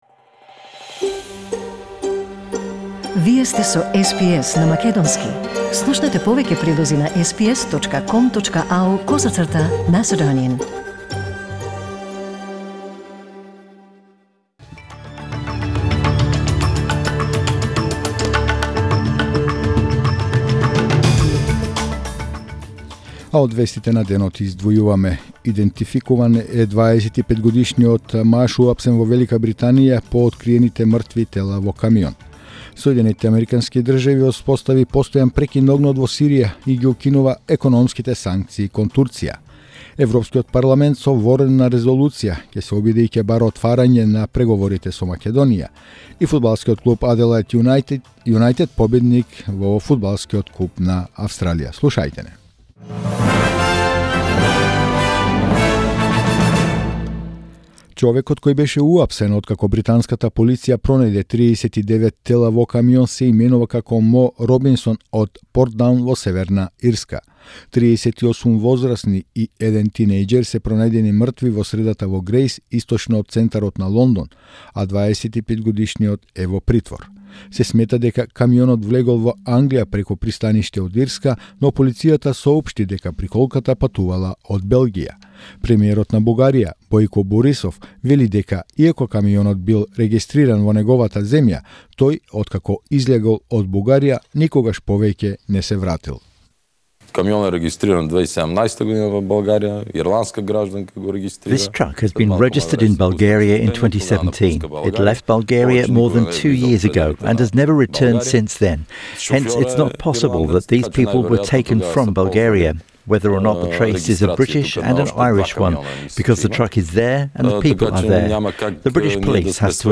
SBS News in Macedonian 24 October 2019